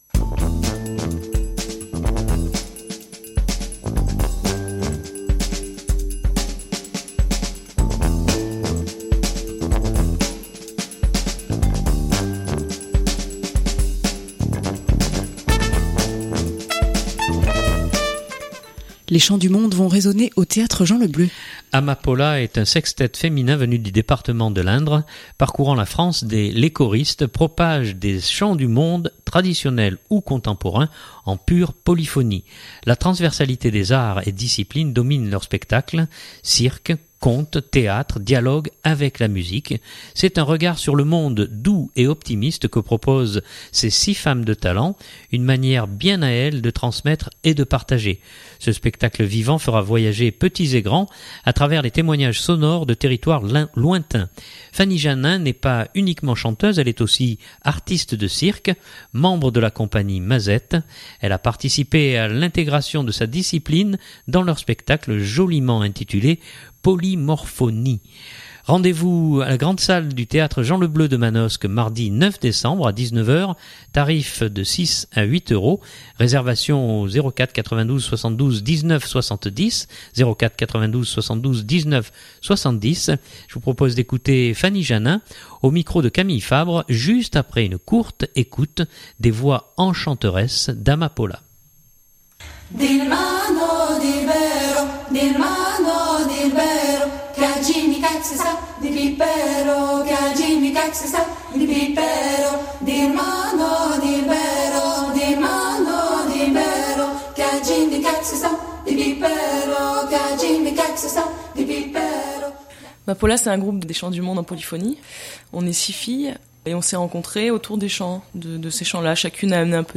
Amapola est un sextet féminin venu du département de l’Indre. Parcourant la France, les choristes propagent des chants du monde, traditionnels ou contemporains, en pure polyphonie.